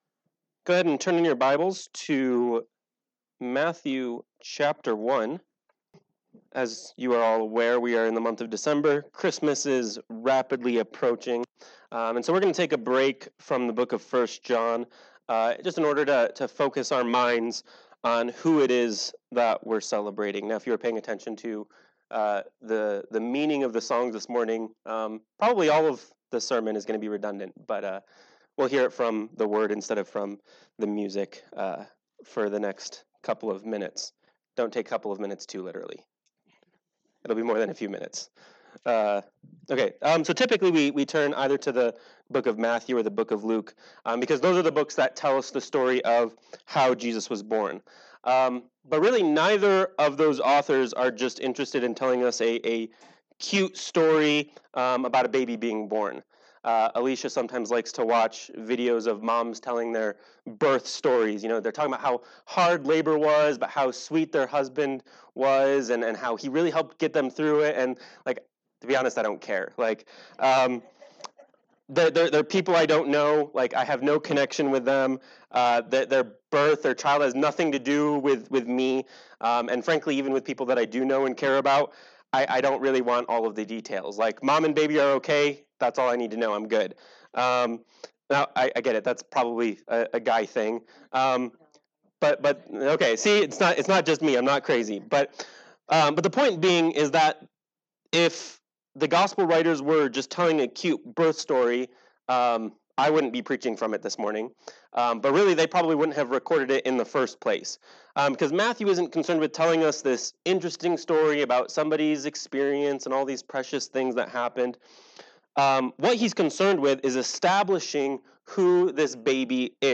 Matthew Chapter 1 Service Type: Sunday Morning Worship « 1 John 3:4-10 Matthew 2:1-12